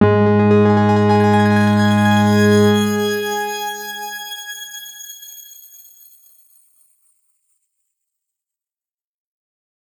X_Grain-G#2-ff.wav